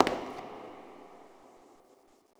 succubus_step_09.wav